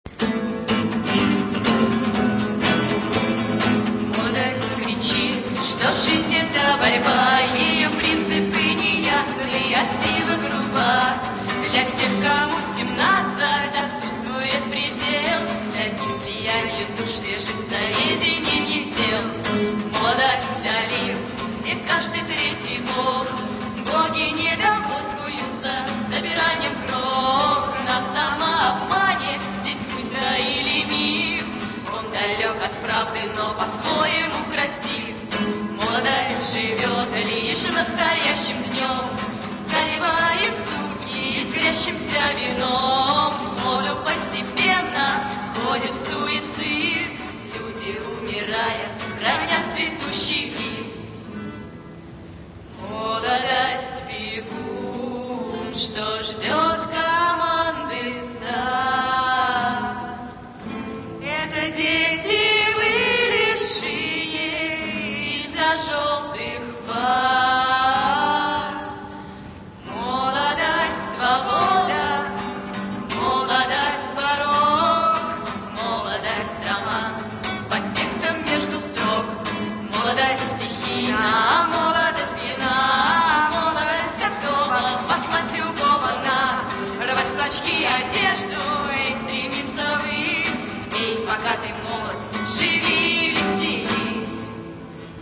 278 kb, авторское исполнение